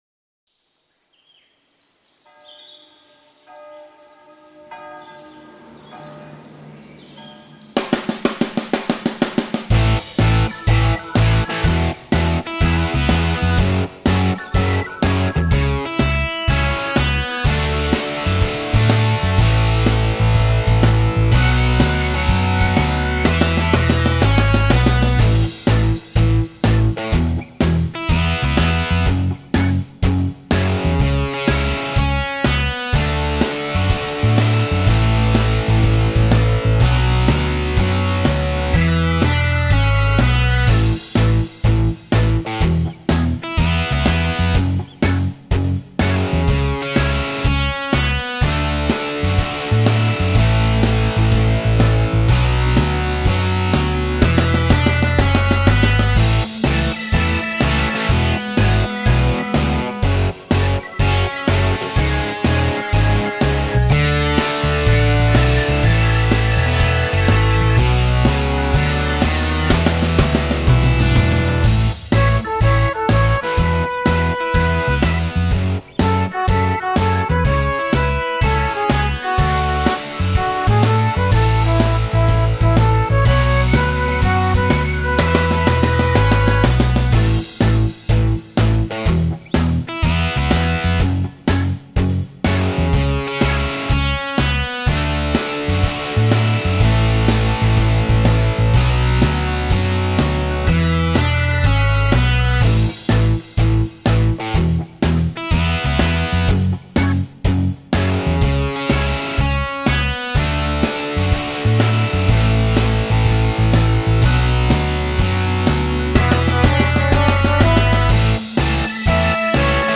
Karaoke i els vídeos originals.